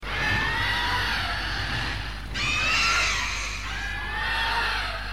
Melba_Roar.ogg